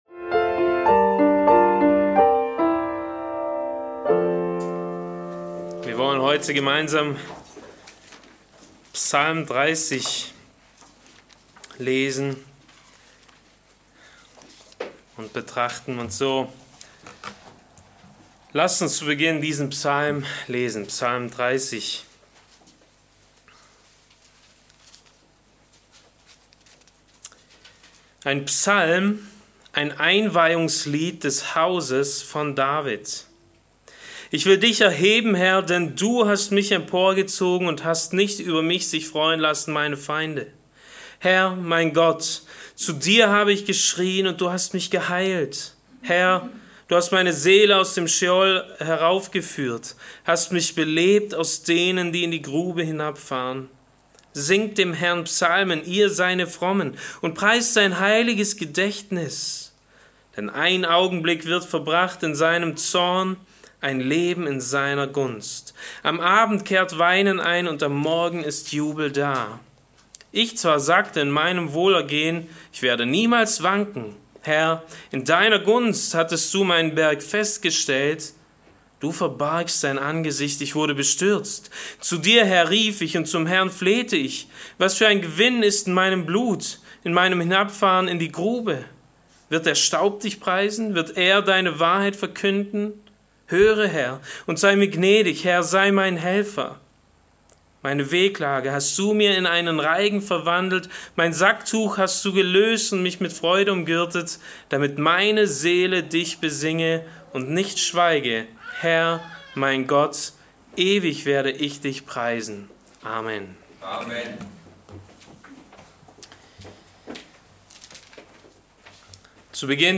Allein aus Gnade - Bibeltreue Predigten der Evangelisch-Baptistischen Christusgemeinde Podcast